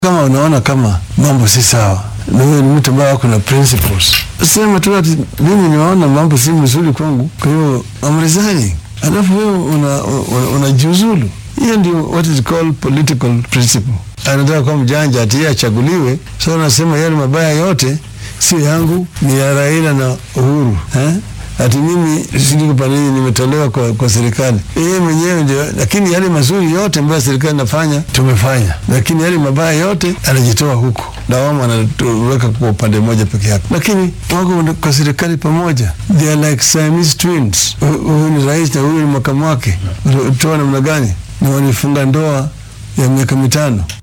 Madaxa xisbiga ODM Raila Odinga oo wareysi siiyay idaacadaha kala ah Inooro FM, Kameme FM, Inooro Tv, Kameme Tv iyo Gukena FM ayaa sheegay in go’aankii 20-kii bishan siddeedaad ka soo baxay maxkamadda racfaanka ee dalka ee lagu joojiyay geeddisocodkii BBI uu Kenyaanka ka hor istaagay faa’iidooyin badan.